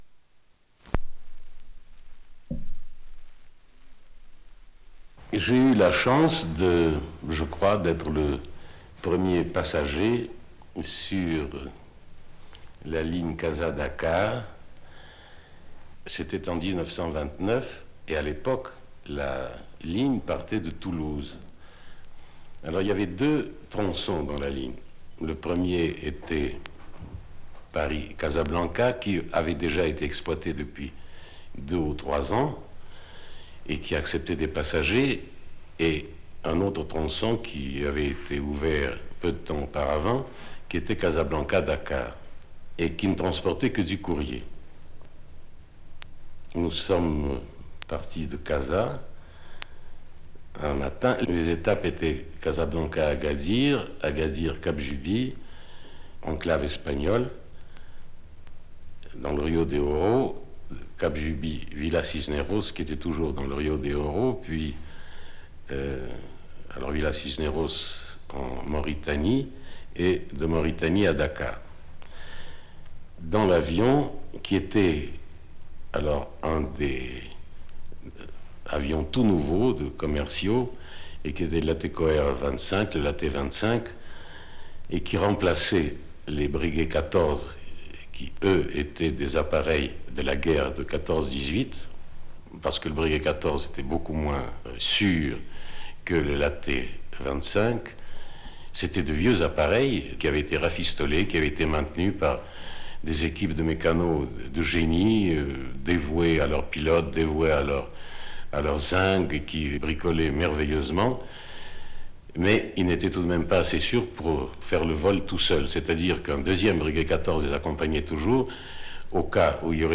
Cet enregistrement est constitué par les récits de onze témoins qui vécurent entre 1918 et 1933, la conquête aérienne de l’Atlantique Sud